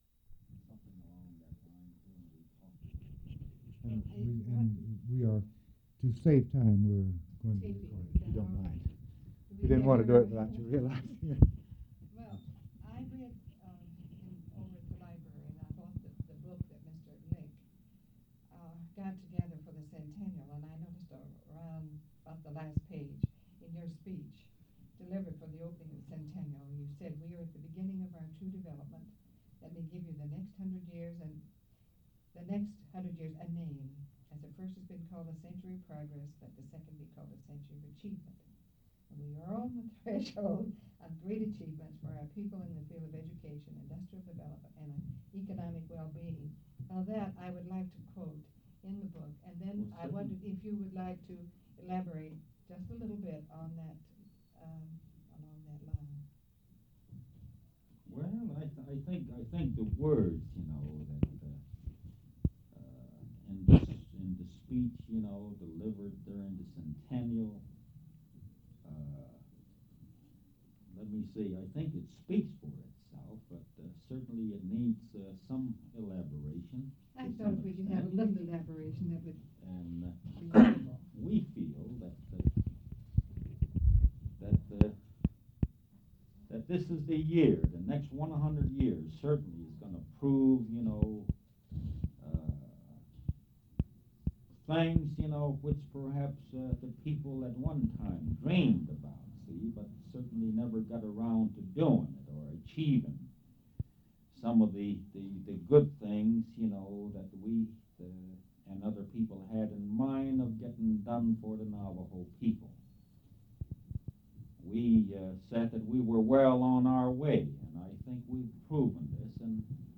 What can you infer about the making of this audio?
Audio/mp3 Original Format: Audio cassette tape Resource Identifier